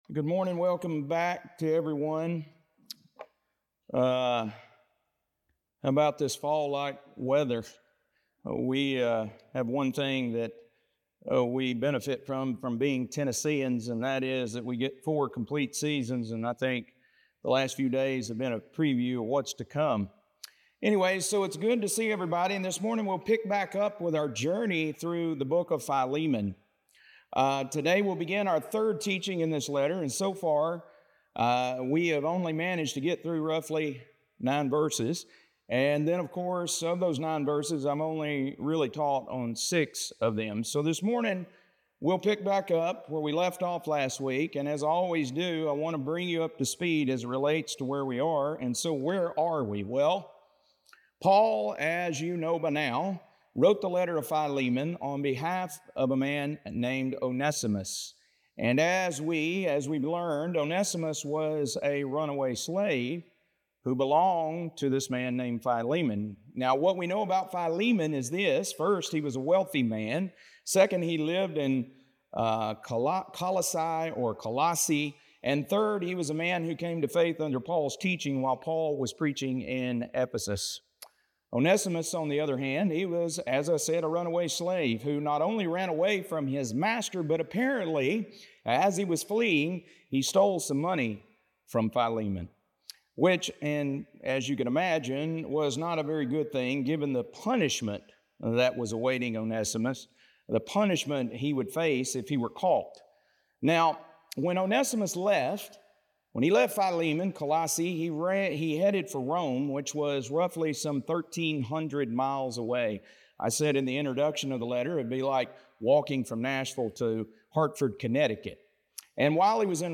Today will be in our 3rd teaching in this letter and so far, we only managed to get through roughly 9 verses, and then of course of those 9 verses, I have only taught 6 of them.